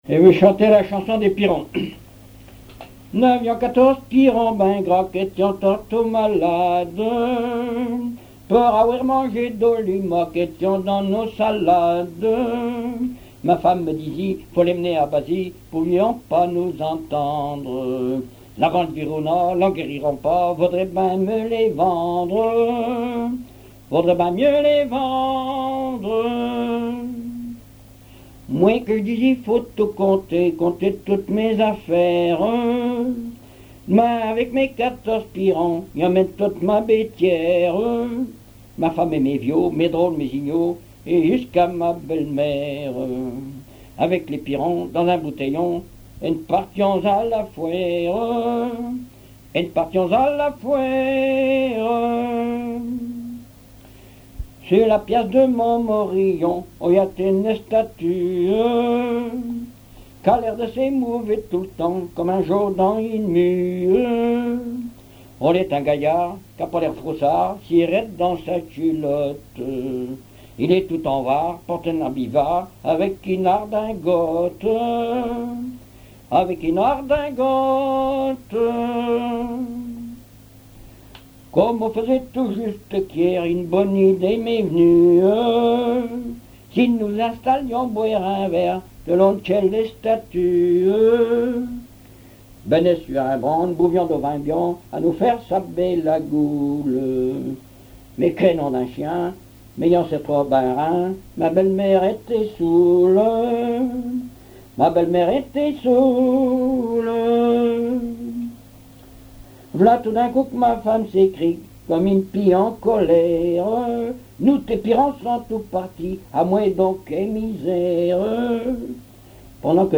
Patois local
Pièce musicale inédite